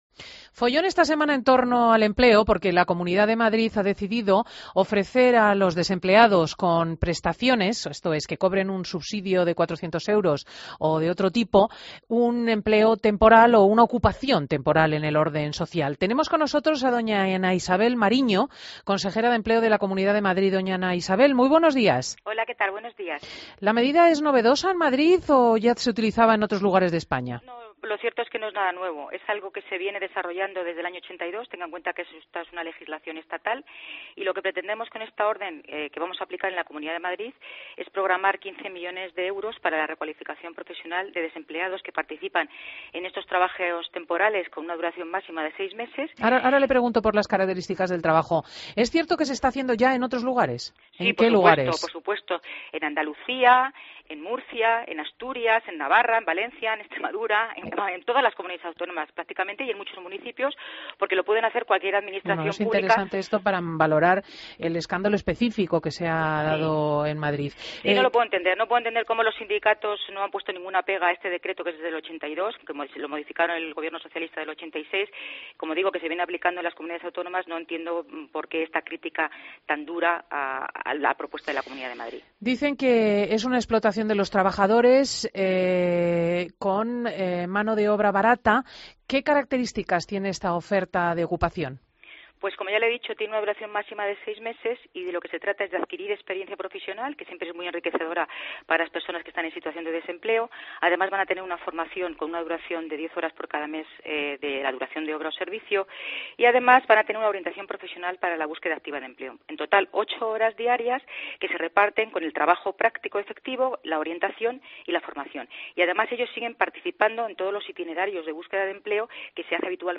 Escucha la entrevista a Ana Isabel Mariño, consejera de Empleo de la Comunidad de Madrid